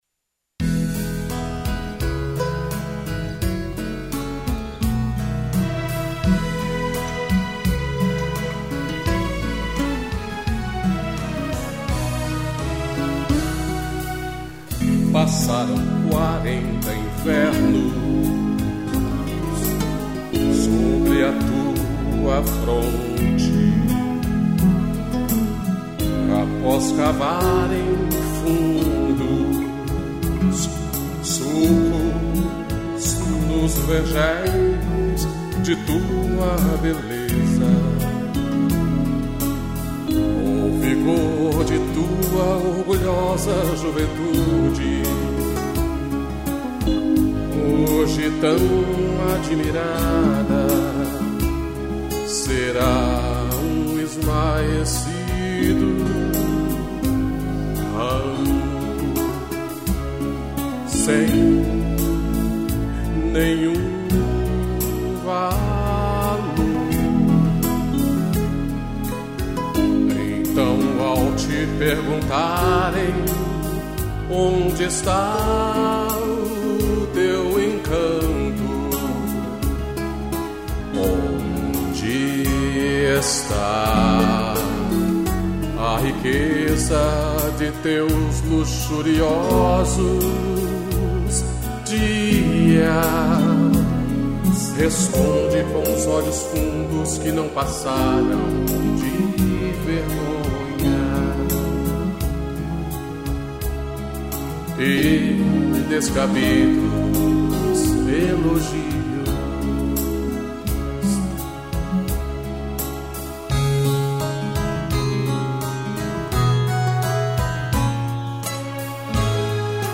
interpretação e violão
piano